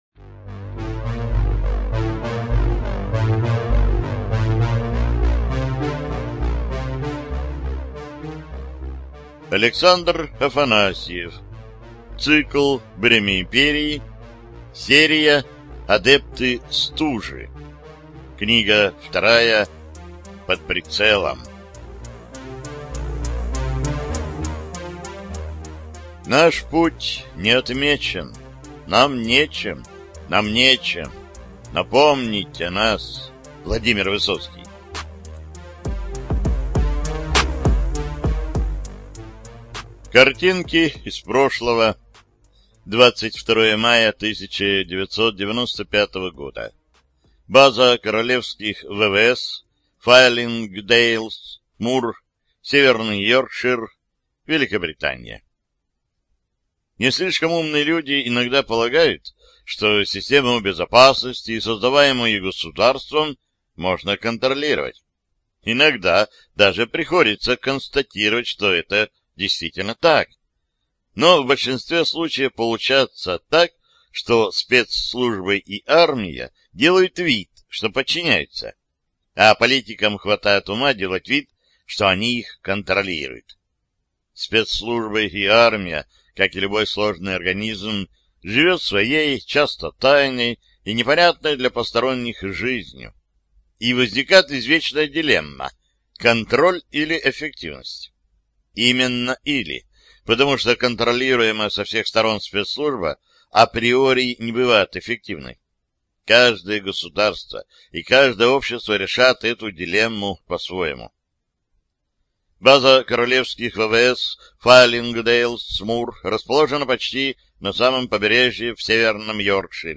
ЖанрФантастика, Боевики, Альтернативная история